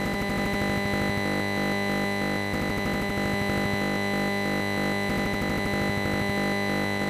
LTE NB-IoTInternet of Things signal from a Nokia transmitter.